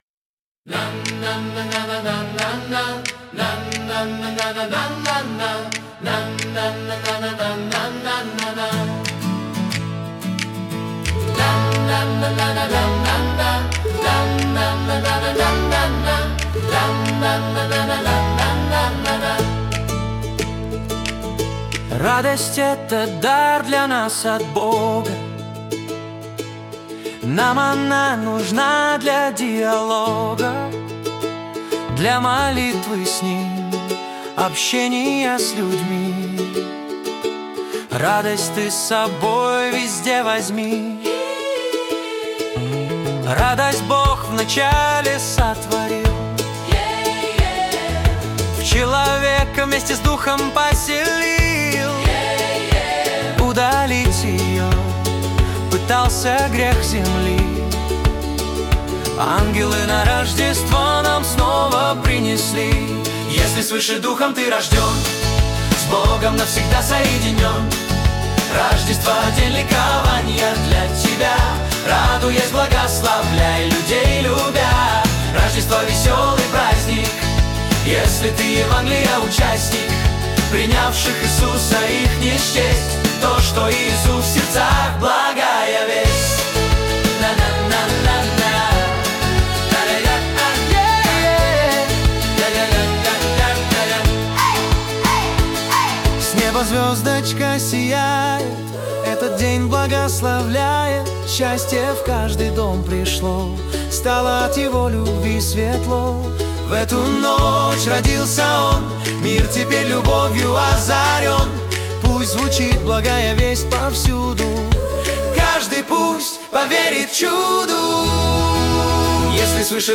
песня ai
187 просмотров 579 прослушиваний 58 скачиваний BPM: 90